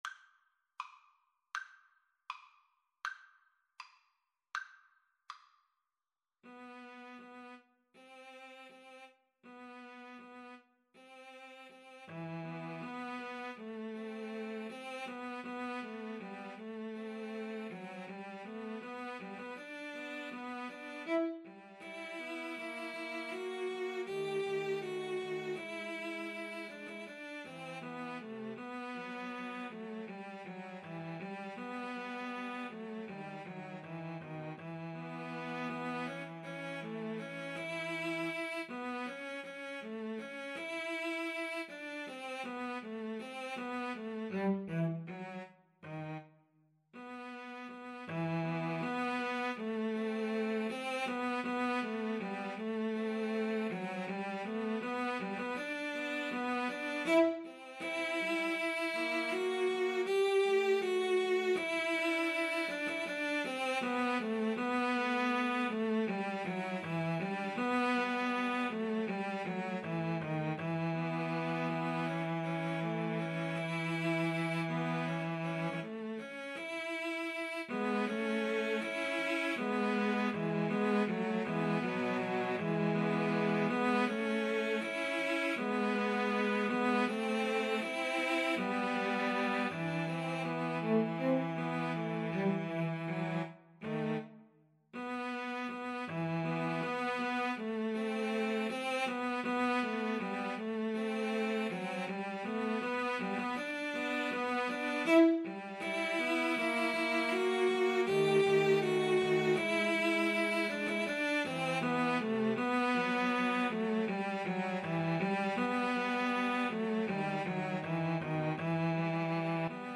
Play (or use space bar on your keyboard) Pause Music Playalong - Player 1 Accompaniment Playalong - Player 3 Accompaniment reset tempo print settings full screen
E minor (Sounding Pitch) (View more E minor Music for Cello Trio )
~ = 100 Andante
Classical (View more Classical Cello Trio Music)